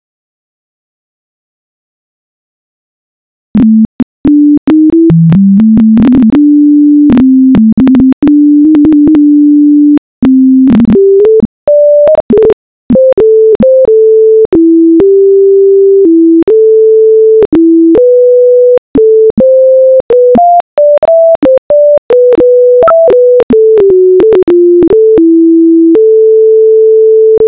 E não é que o danado tocou e ainda fez gracinha (ou “melismou”, no jargão musical).
blues_1_sint_viol.wav